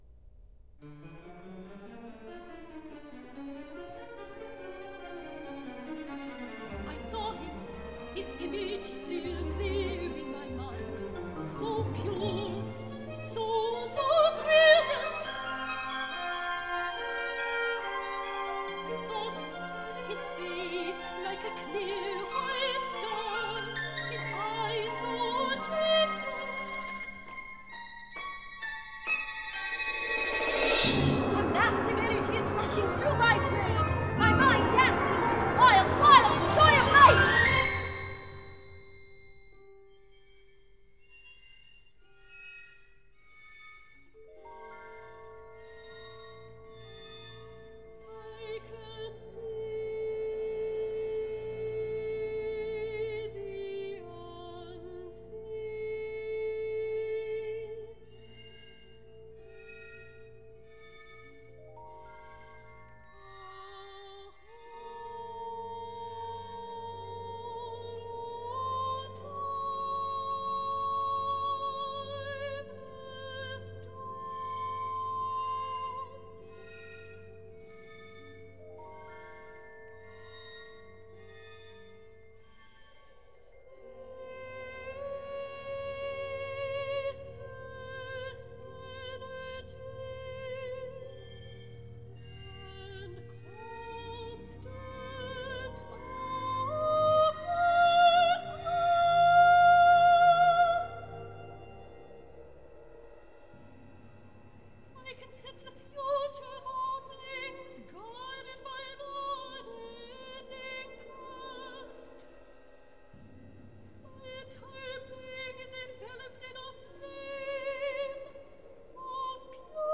soprano
Recorded in 1992 at the Centennial Concert Hall in Winnipeg